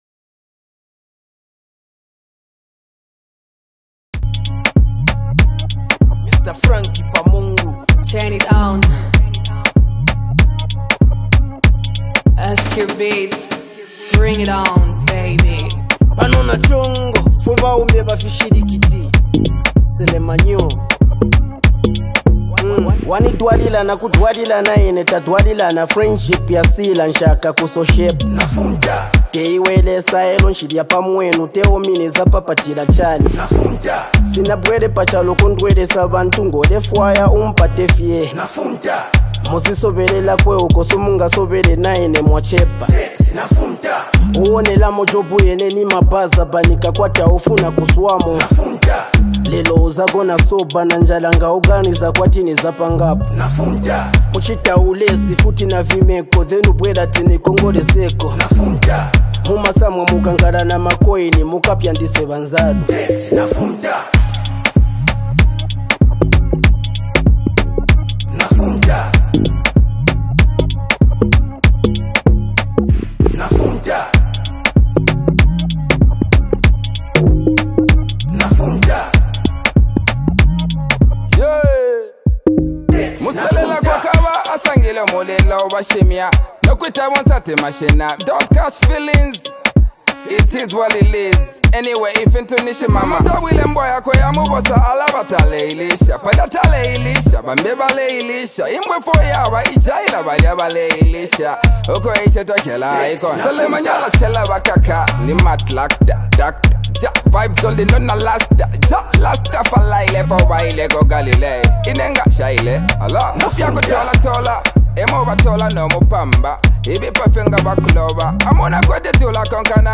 Discover the irresistible fusion of hip-hop and Afrobeat